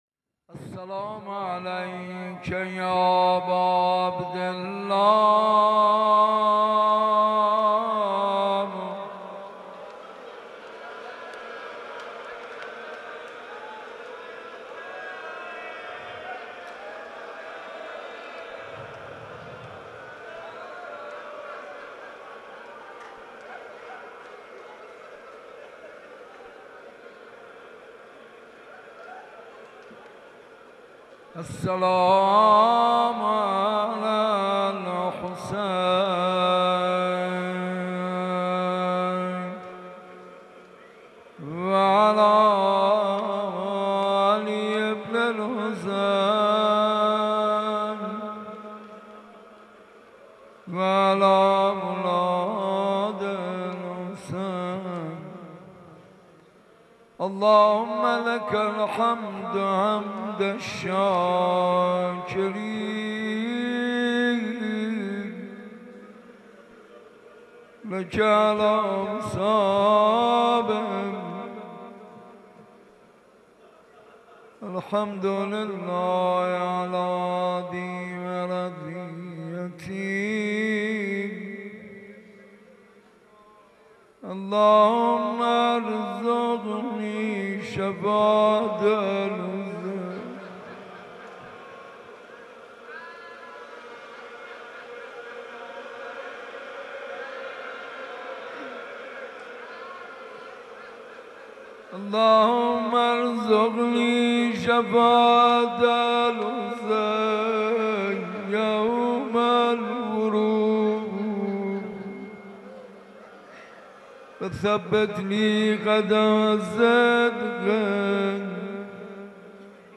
جام جم ماه روی تو سرود محمود کریمی